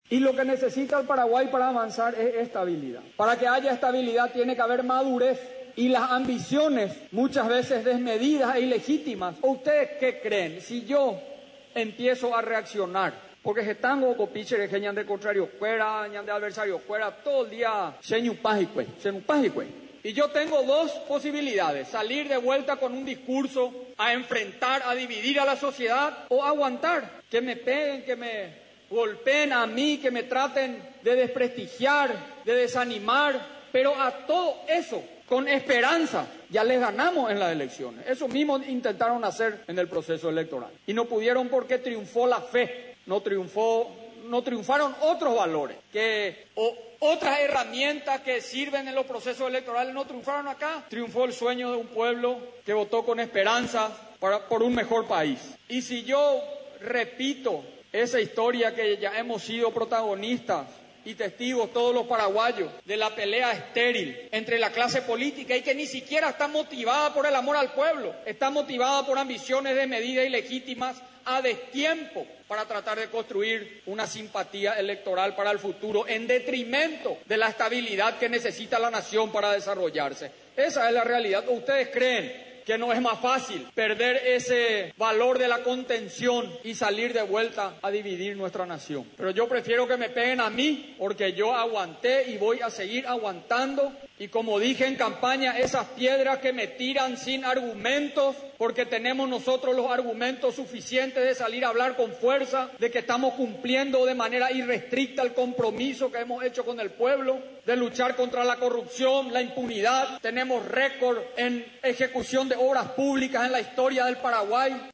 Gobierno entregó este viernes, millonario aportes en educación y salud para el departamento de Caazapá, en un acto realizado en el polideportivo Inmaculada Concepción, con presencia del presidente de la República, Mario Abdo Benítez.